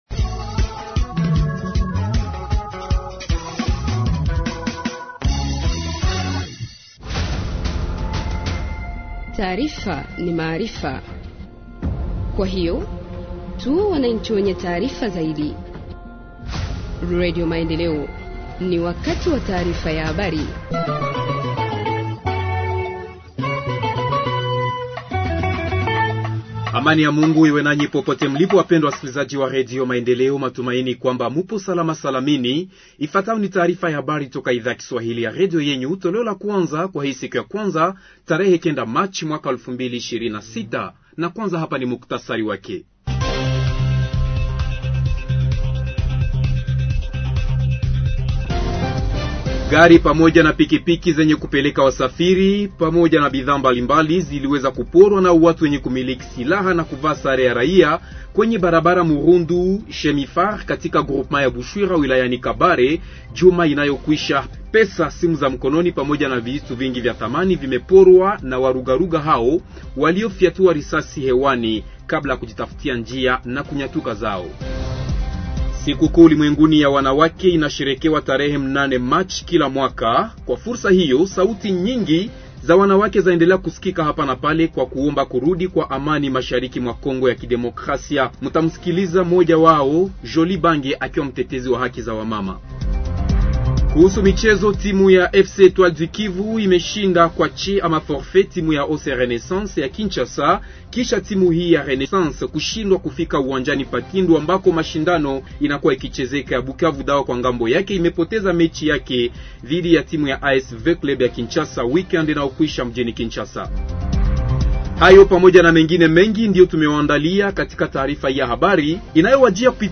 Journal en swahili du 09 mars 2026 – Radio Maendeleo